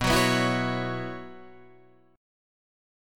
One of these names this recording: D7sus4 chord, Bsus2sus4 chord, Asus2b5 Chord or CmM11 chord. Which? Bsus2sus4 chord